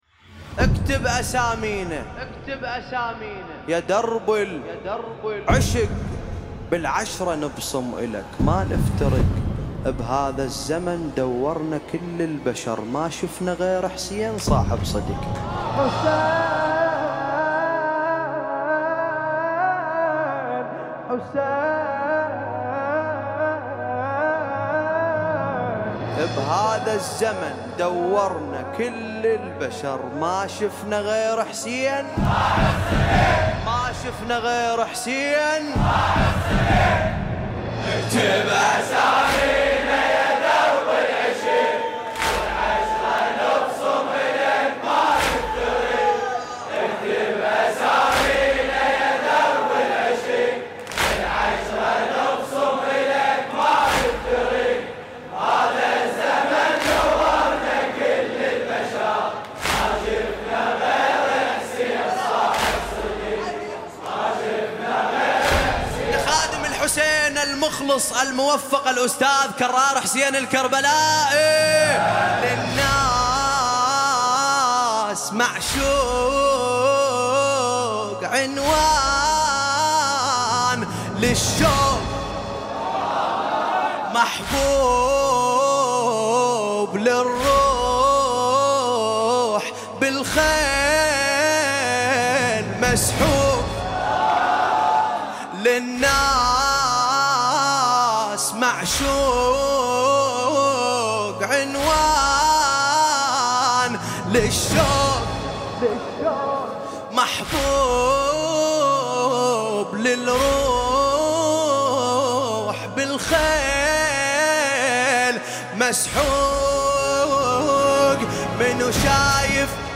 لطمية